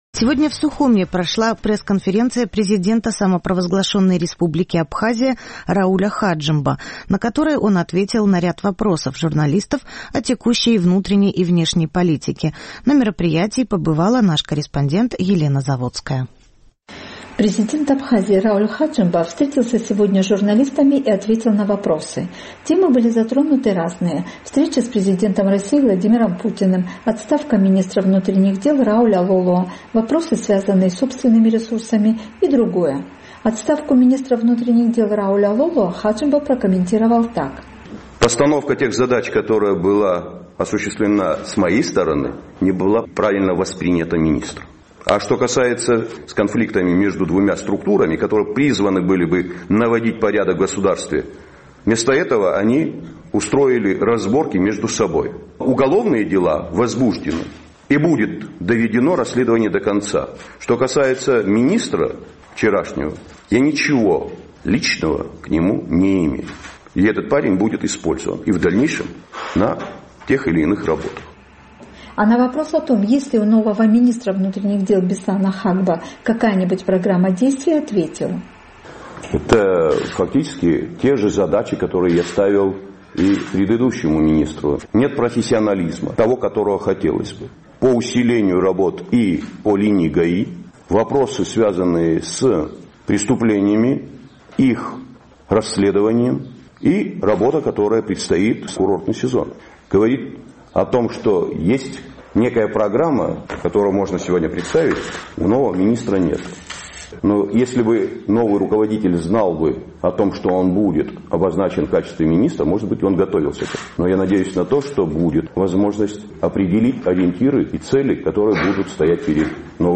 Сегодня в Сухуме прошла пресс-конференция президента Абхазии Рауля Хаджимба, на которой он ответил на вопросы журналистов о текущей внутренней и внешней политике.